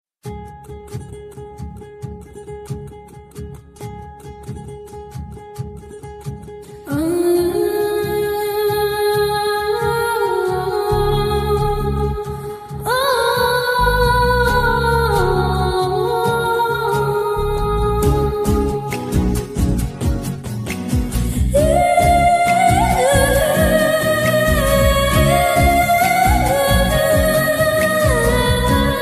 pulse-pounding beats